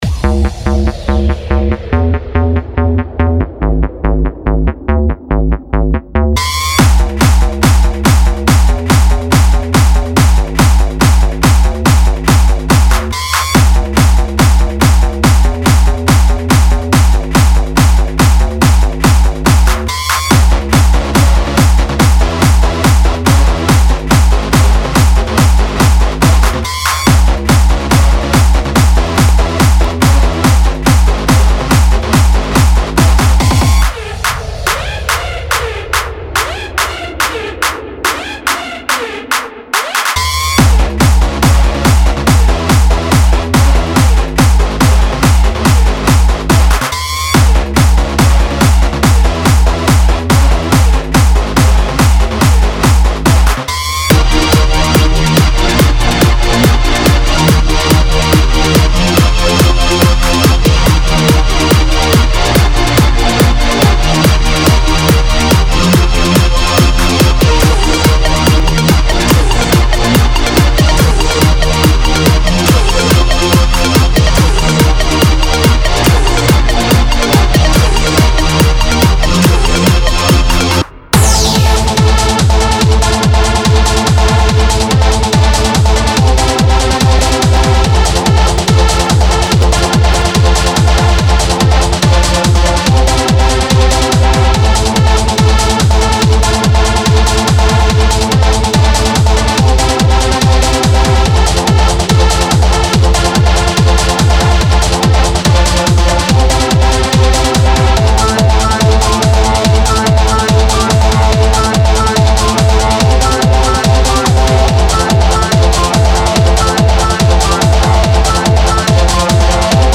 with edgier,harder, more aggressive loops
and synth phrases to keep the floor sweating
Intense SFX hits and production effects complete